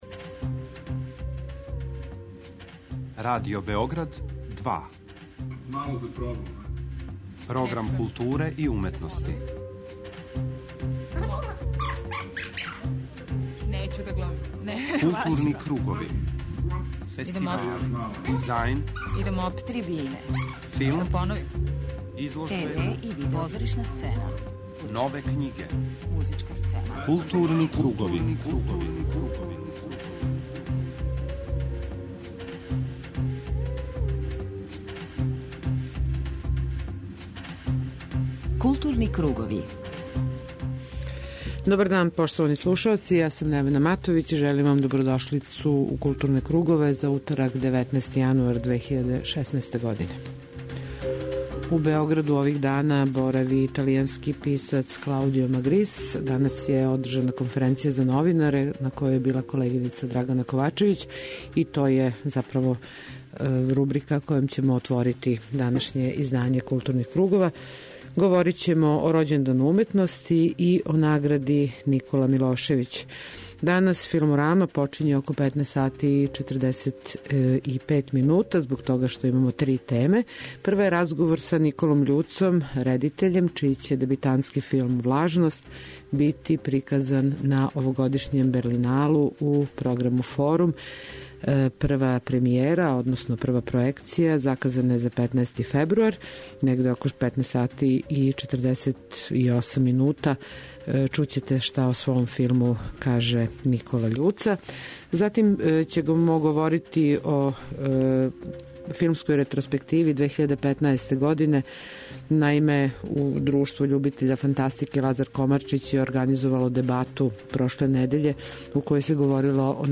На самом почетку темата чућете разговор са нашим редитељем чији ће дебитантски филм имати светску премијеру на овогодишњем Берлиналу, у програму Форум.